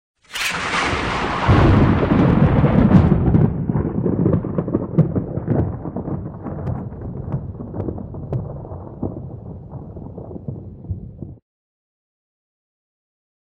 Звуки грозы, грома
Сверкнула яркая молния